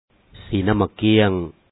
sǐi-nàm bák kǐaŋ Orange